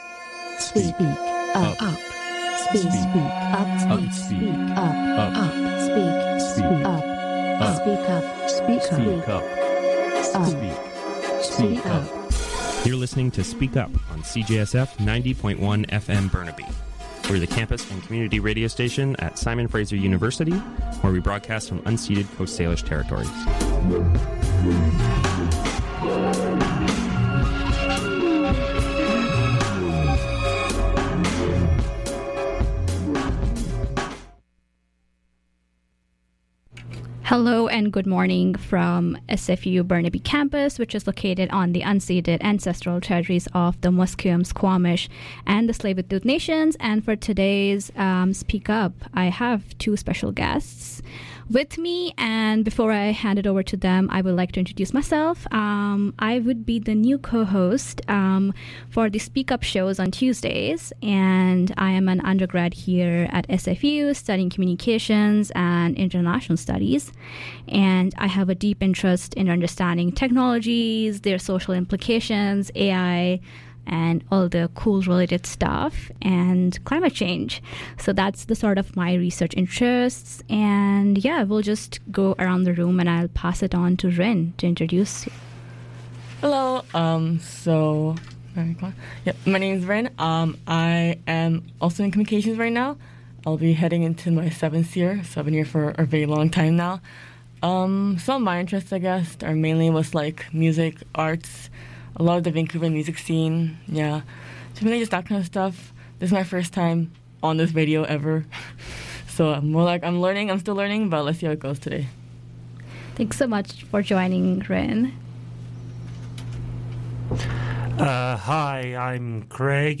Today's program features a conversation about consent